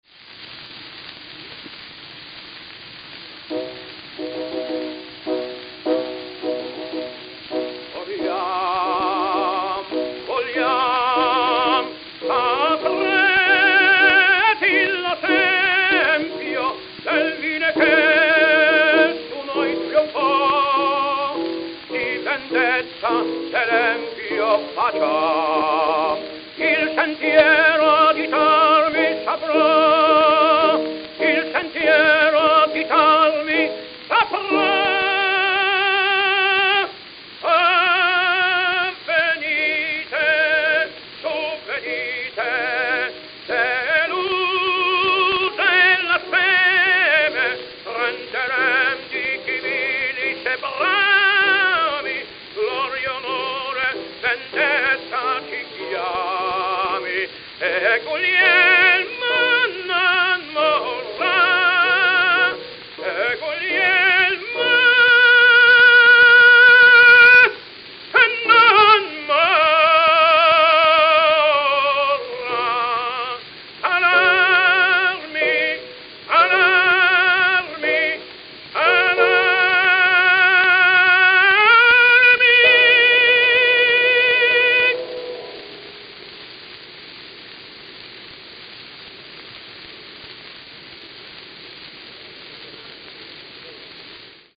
Victor Red Seal 78 RPM Records
Francesco Tamagno
Ospedaletti, Italy
Note: played at 77 RPM. Talking before and after selection.